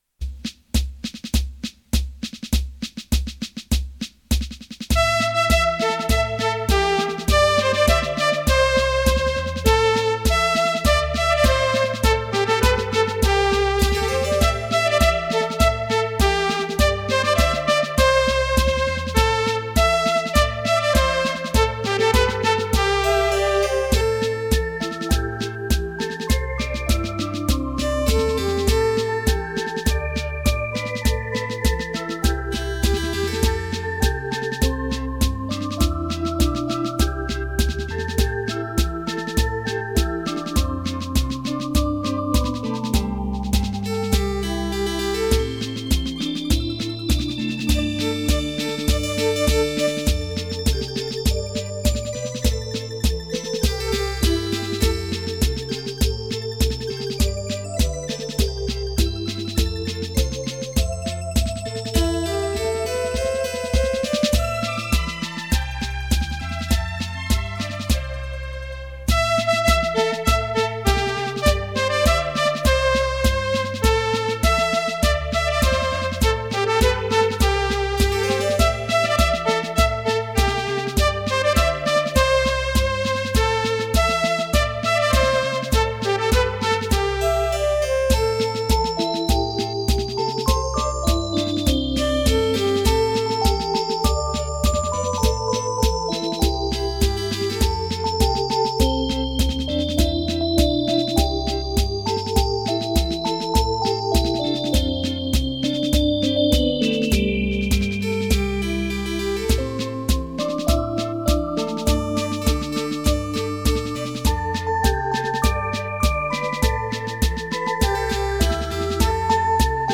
轻快、跳耀、如首首小诗中吟诵，如条条小溪在流淌，如阵阵清风在抚慰，这如歌的行板呀！